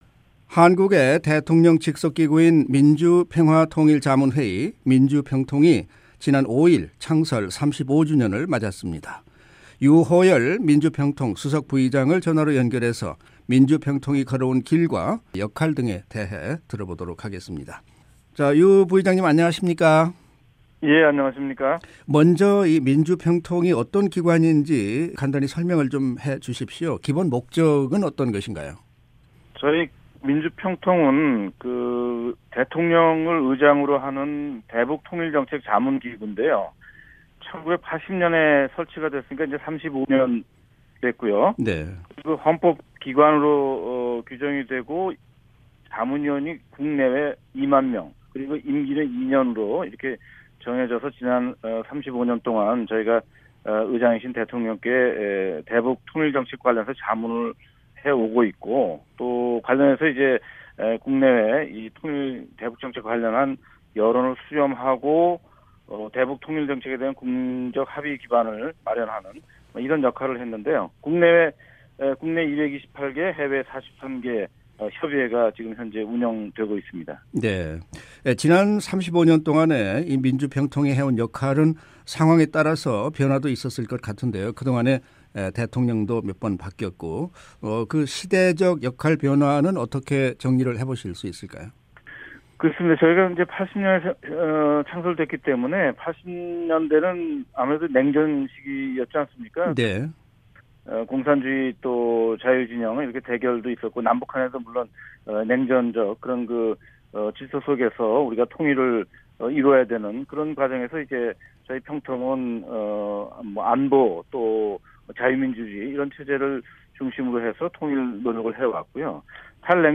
[인터뷰 오디오: 유호열 평통 수석부의장] 평통 창설 35주년 의미와 역할